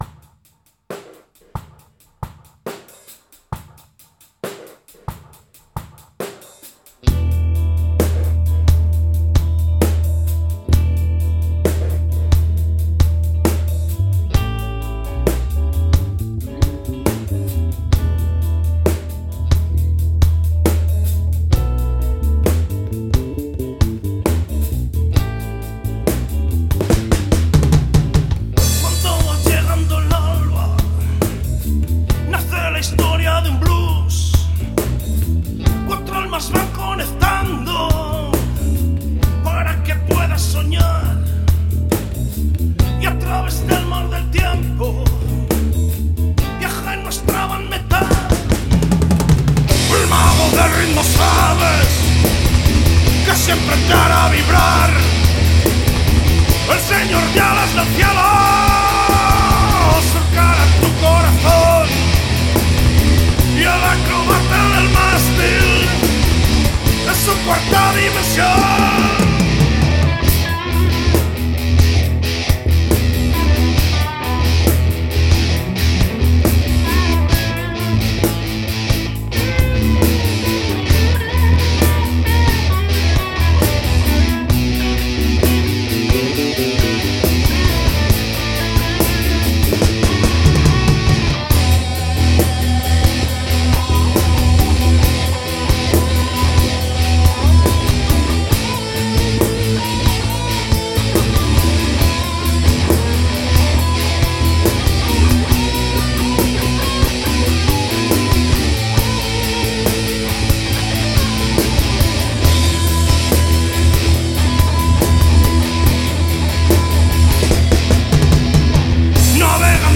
Guitarra Solista
Guitarra Ritmica
Bajo y Voz
Bateria